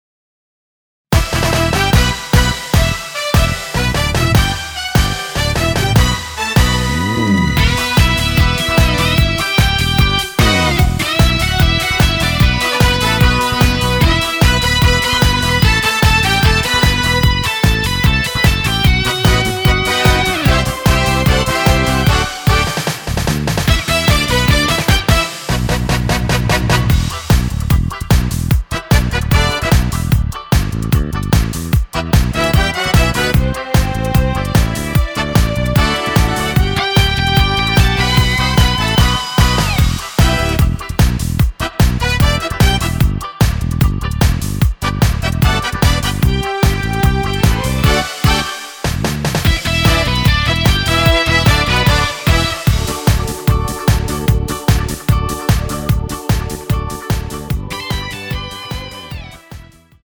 원키에서(+5)올린 MR입니다.(미리듣기 참조)
Em
앞부분30초, 뒷부분30초씩 편집해서 올려 드리고 있습니다.